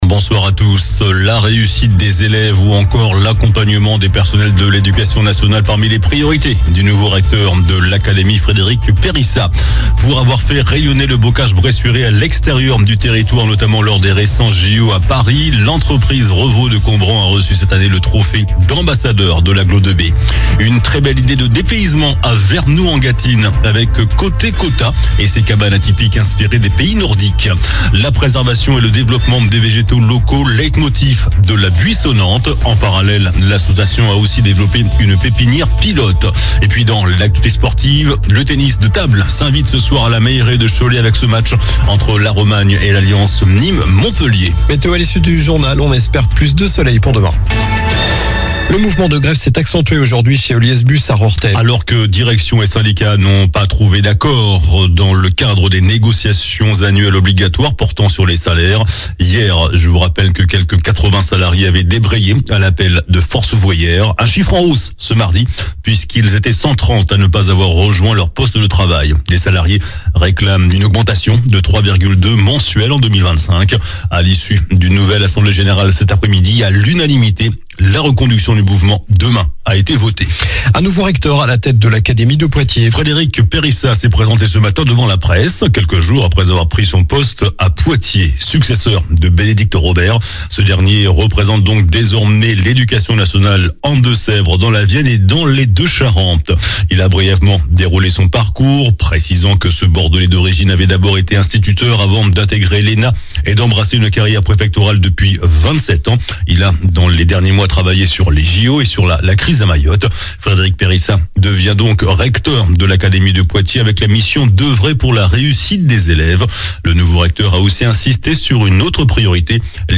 JOURNAL DU MARDI 29 OCTOBRE ( SOIR )